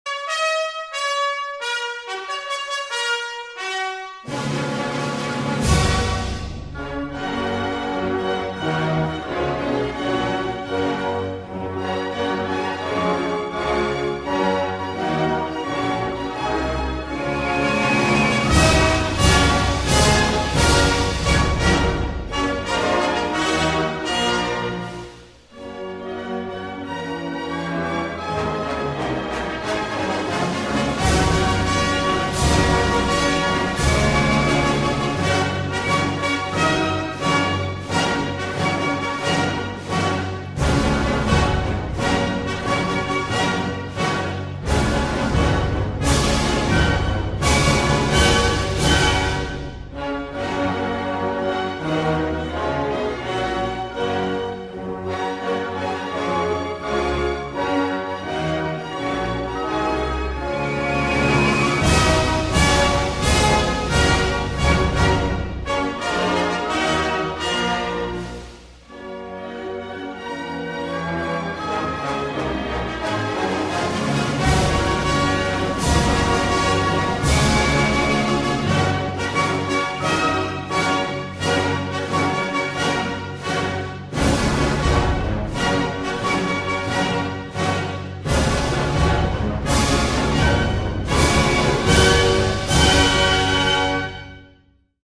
gt2.png  国歌自动伴奏
91s国歌